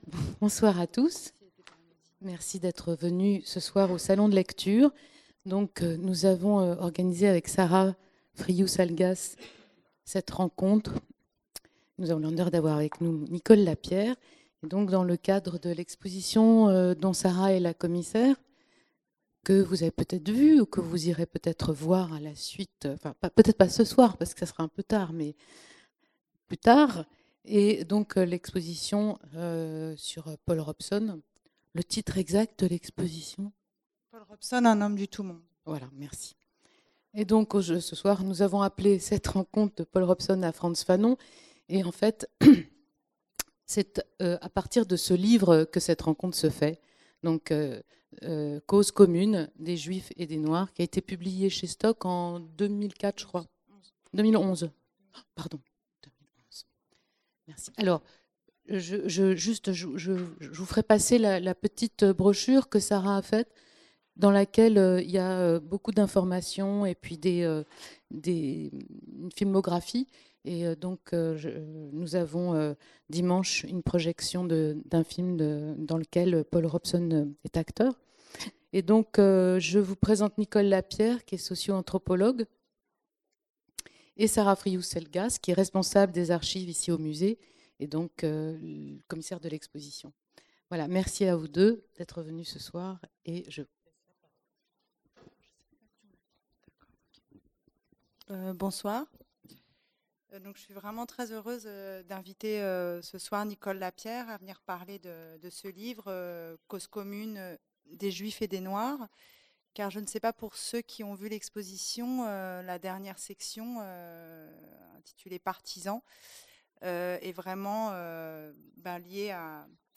conférence enregistrée au Salon de lecture Jacques Kerchache le vendredi 21 septembre 2018